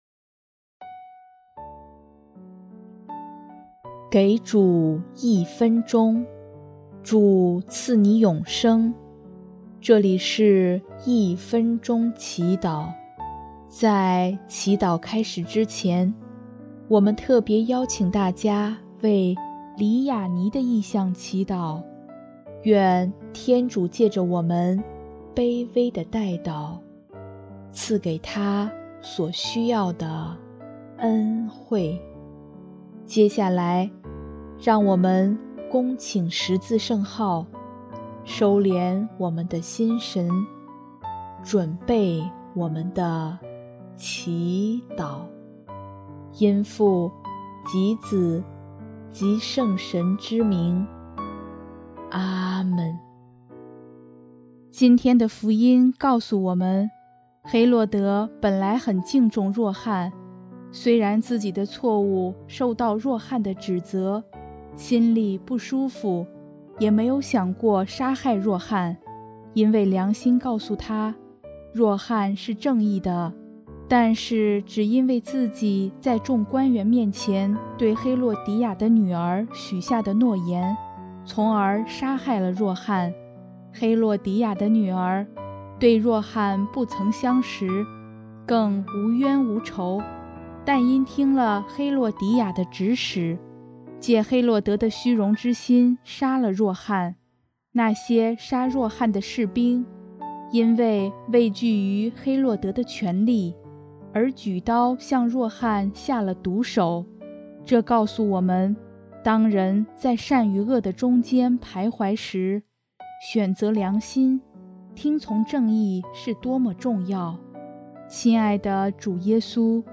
音乐： 主日赞歌《预备主道路》